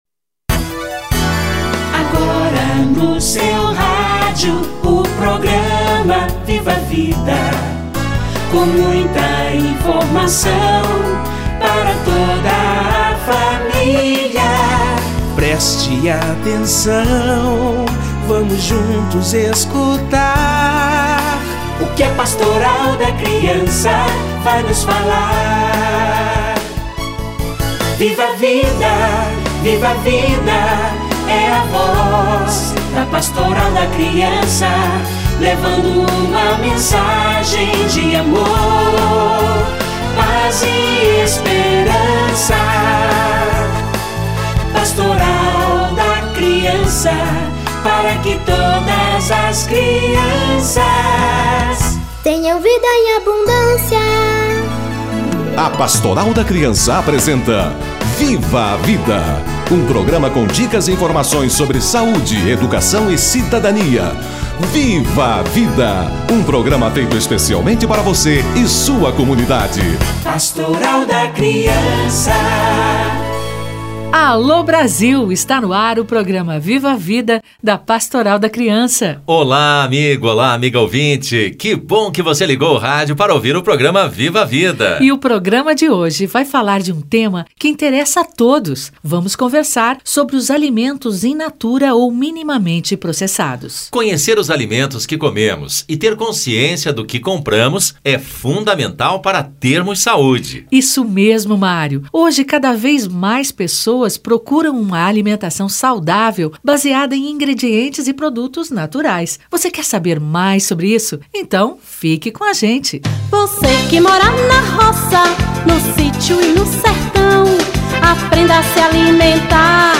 Rotulagem de alimentos - Entrevista